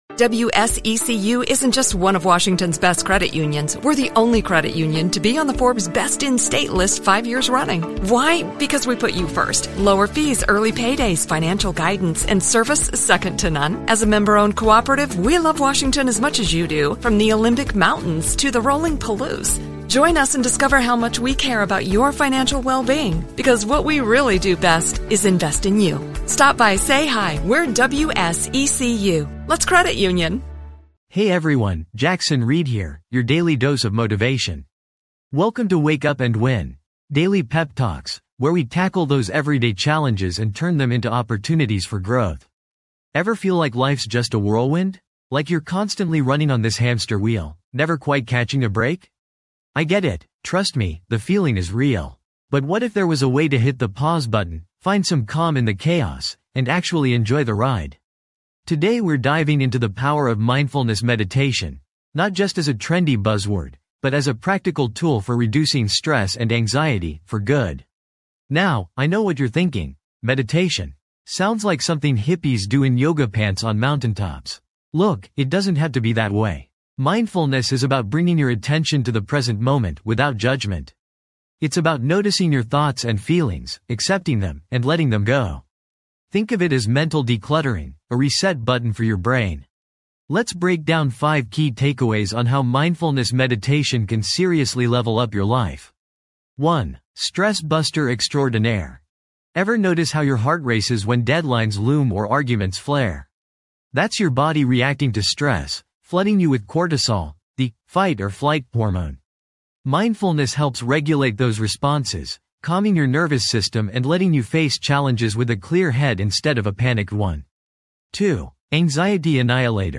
Unwind and de-stress with a guided mindfulness meditation session designed to help you find inner calm and reduce anxiety levels.
- Experience a soothing guided meditation to help you relax and unwind
This podcast is created with the help of advanced AI to deliver thoughtful affirmations and positive messages just for you.